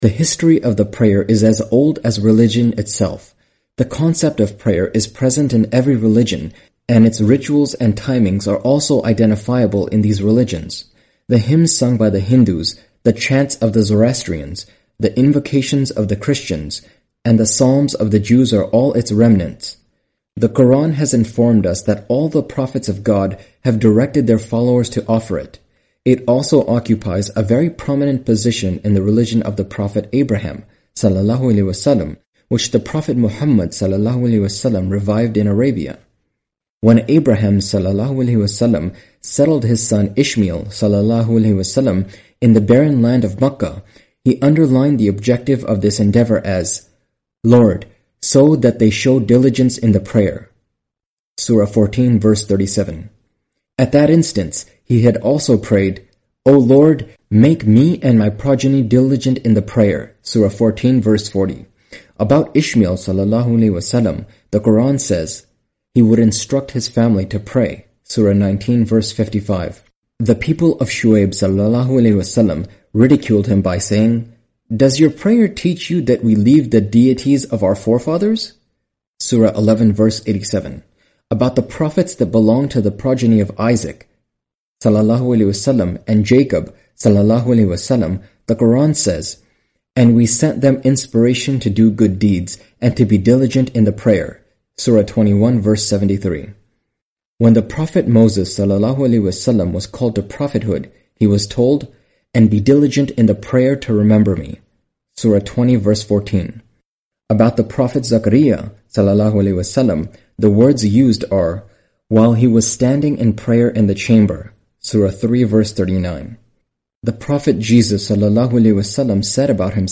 Audio book of English translation of Javed Ahmad Ghamidi's book "Mizan".